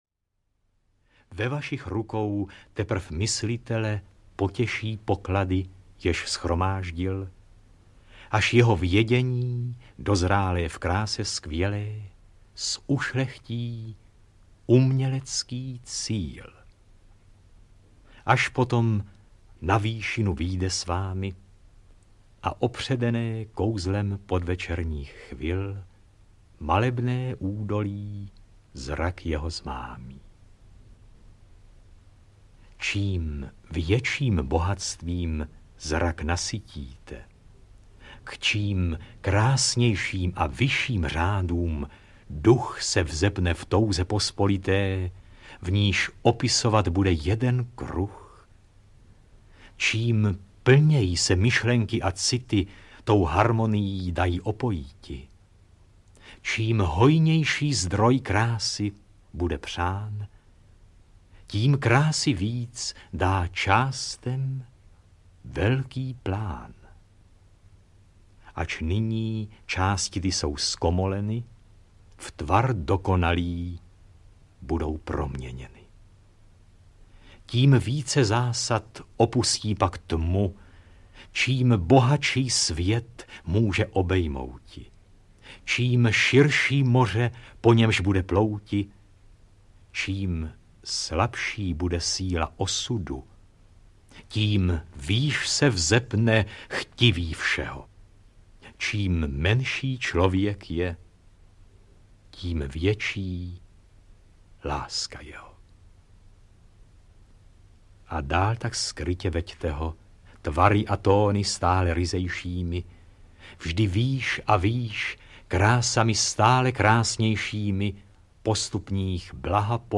Goethe, Schiller, Heine, Rilke, Morgenstern, Brecht....Mistrovská díla německé poezie audiokniha
Ukázka z knihy
goethe-schiller-heine-rilke-morgenstern-brecht-mistrovska-dila-nemecke-poezie-audiokniha